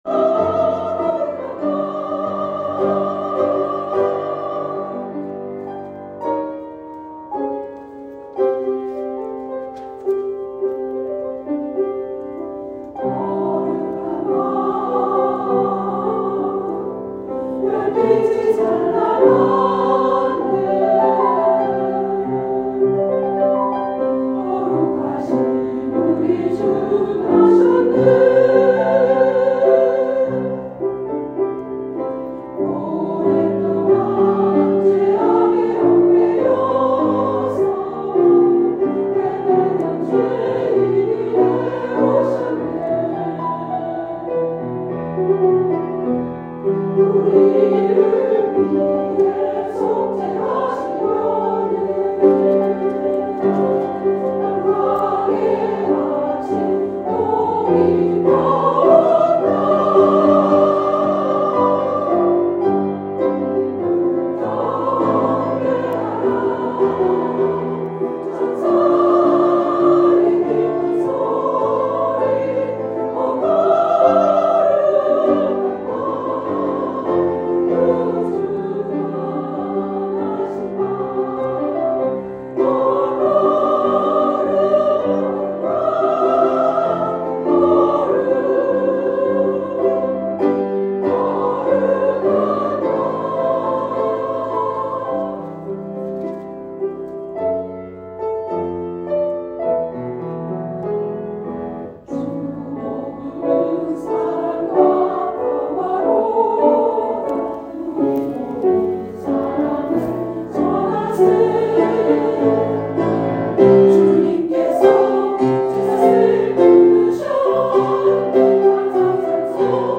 2022년 12월 25일 쥬빌리 찬양대 성탄칸타타
피아노